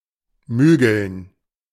Mügeln (German pronunciation: [ˈmyːɡl̩n]